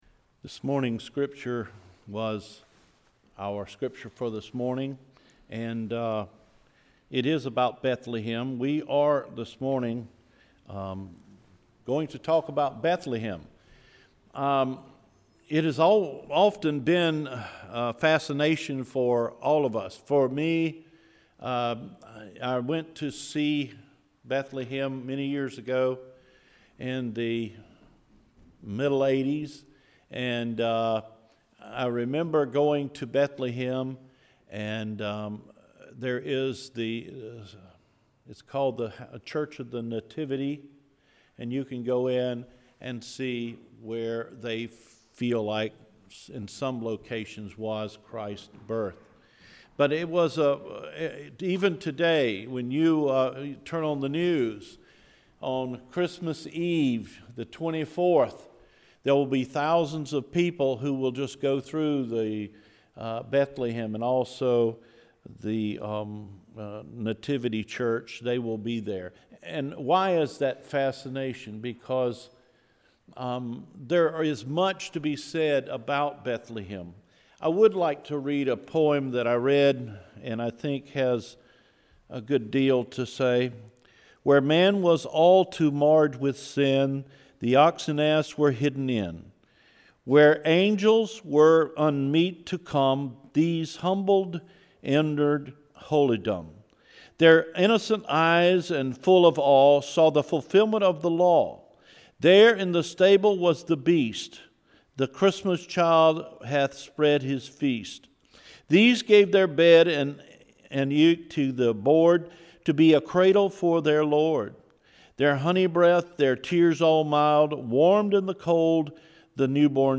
CedarForkSermon-12-3-17.mp3